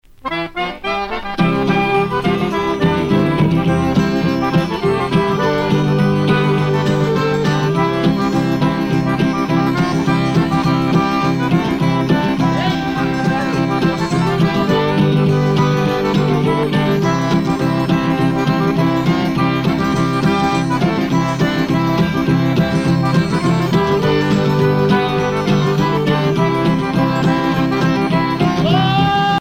danse : two step
Pièce musicale éditée